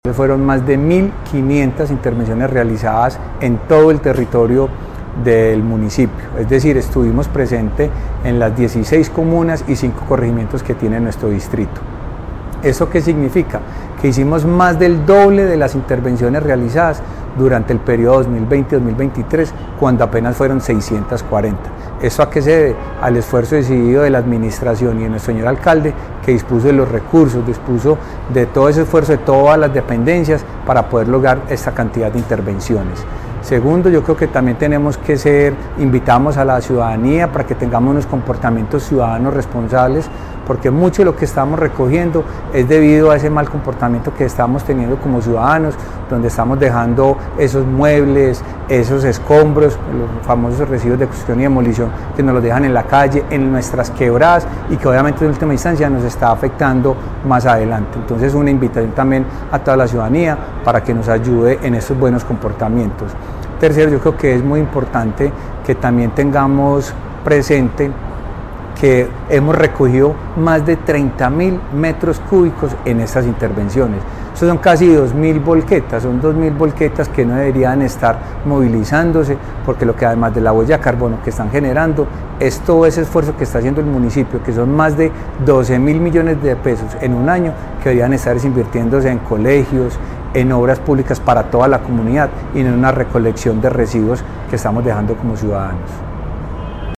Palabras de Carlos Alberto Velásquez, subsecretario de Gestión Ambiental